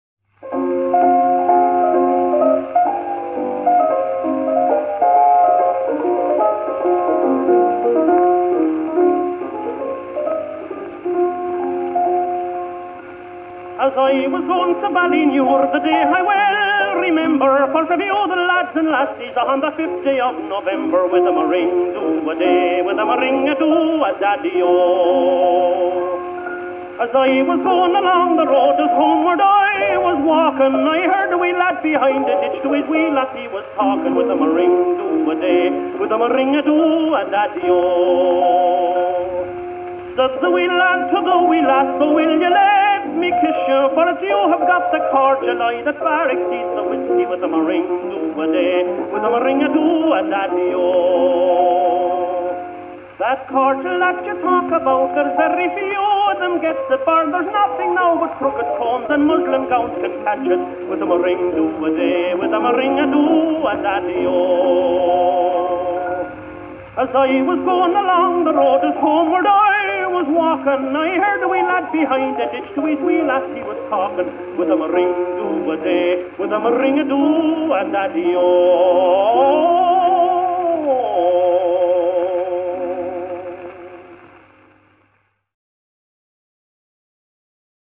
Irish Pub Music 1940 - 1950